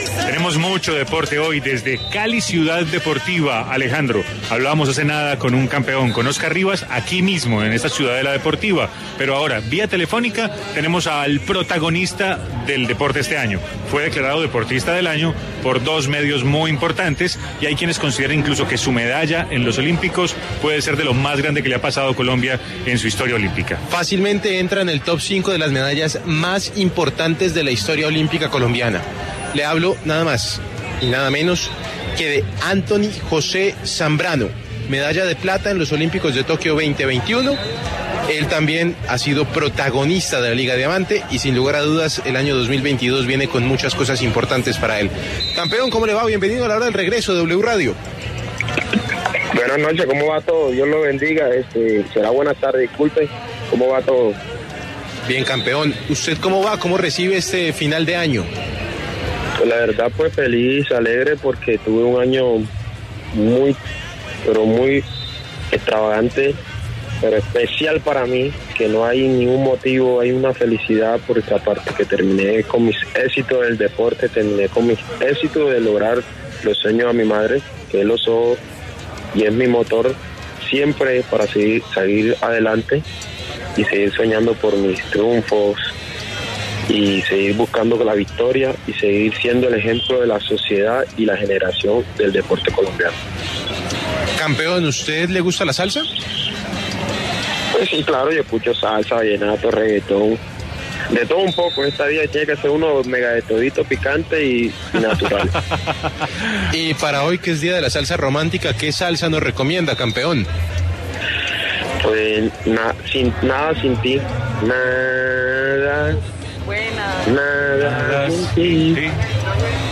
En diálogo con La Hora del Regreso el atleta, quien obtuvo medalla en los Juegos Olímpicos, habló sobre sus planes para la temporada 2022 y su reconocimiento como el mejor deportista del año.